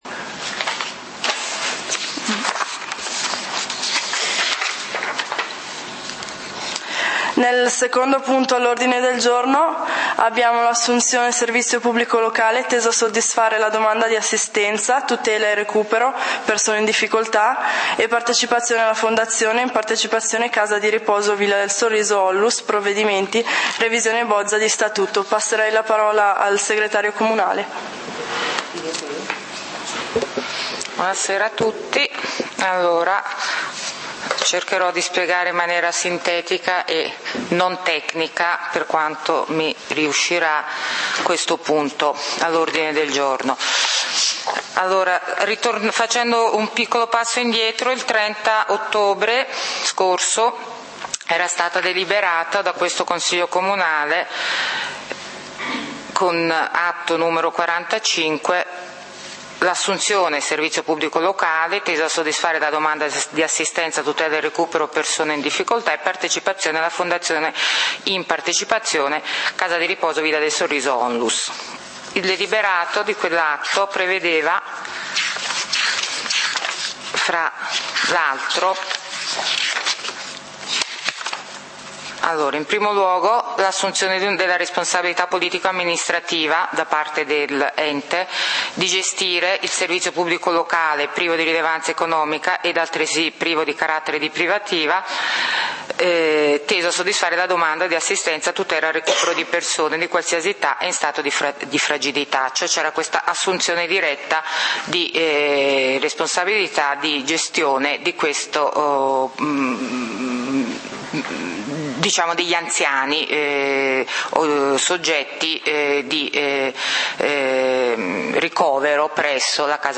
Punti del consiglio comunale di Valdidentro del 27 Dicembre 2012